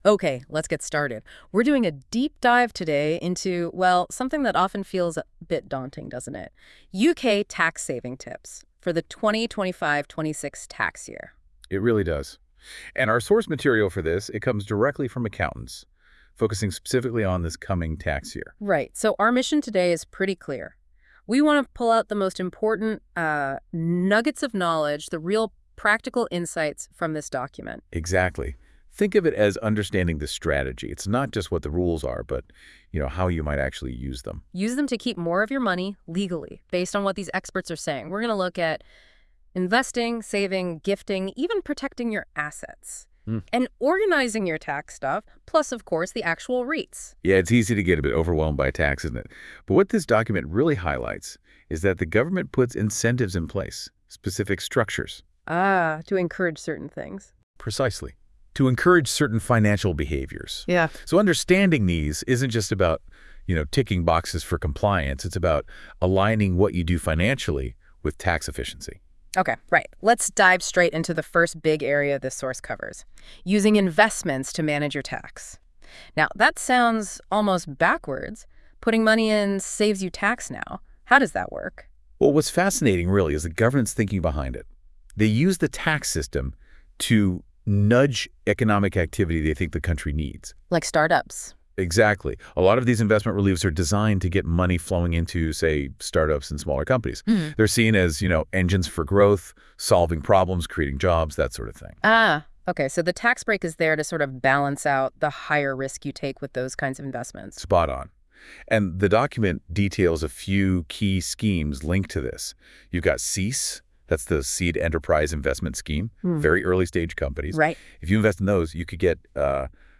Listen to an interesting dialogue via the podcast link